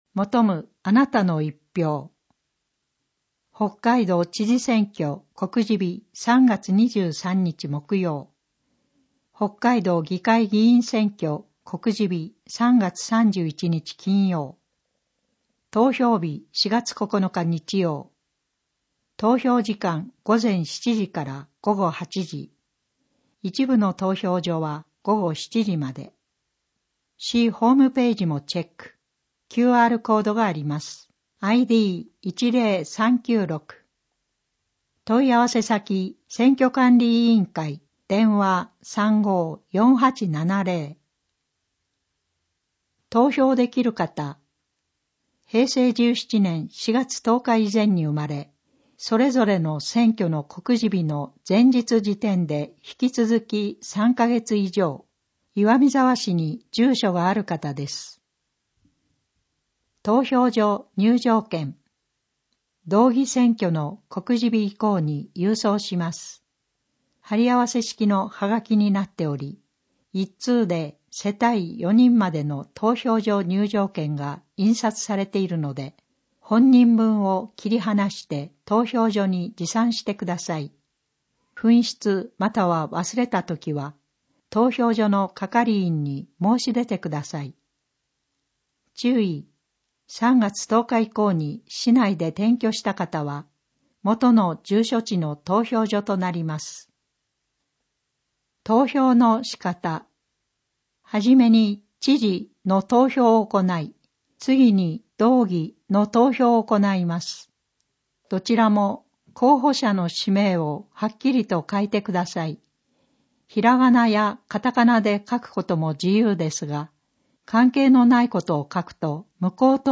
声の広報MP3版は、岩見沢さつきの会にご協力をいただき、録音しているものです。